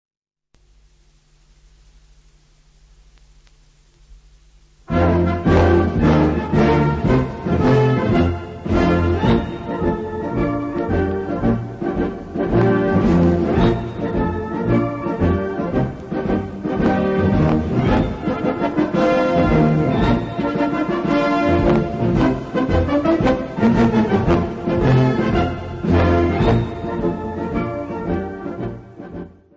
Gattung: Marschiermarsch
Besetzung: Blasorchester